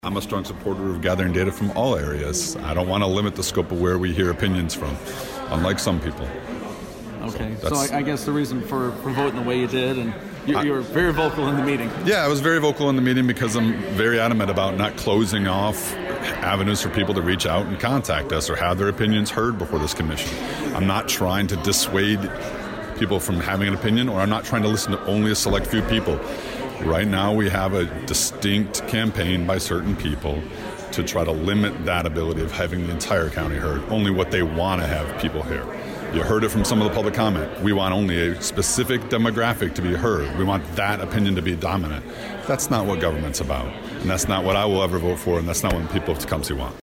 A vocal supporter of ZenCity, David Stimpson…a Republican representing District 1… had this to say after the recent Personnel Ways and Means Committee meeting…